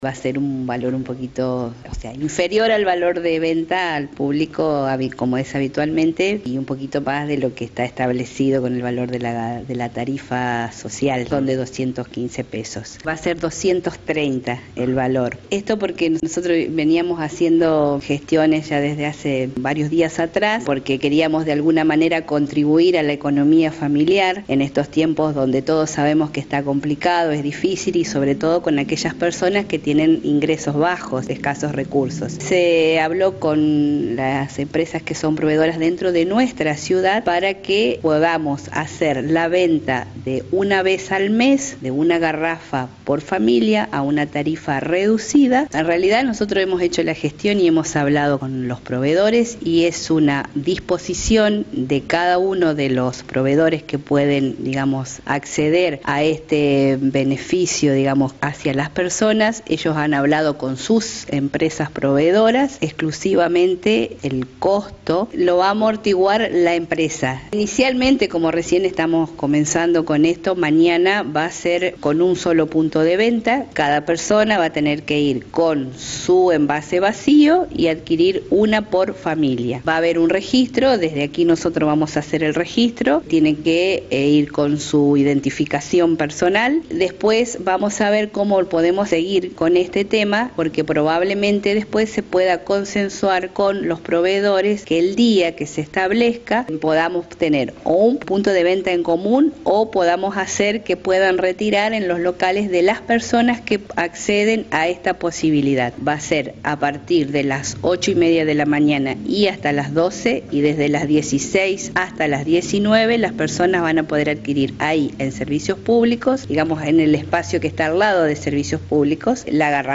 AUDIO: Hebe Capdevilla, Subsecretaria de Promoción Social.